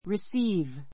receive 中 A2 risíːv リ スィ ー ヴ 動詞 受け取る , 受ける, もらう accept receive a letter from him receive a letter from him 彼から手紙を受け取る I received your letter this morning.